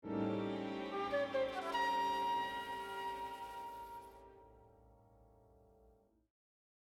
The sound that signals strangeness, surprise, a dreamstate: